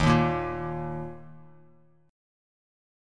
Listen to Eb5 strummed